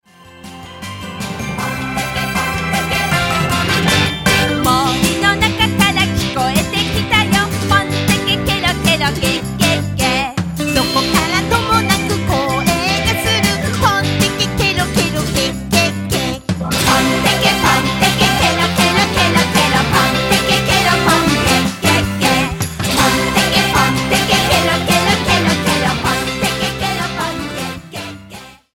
あそびうた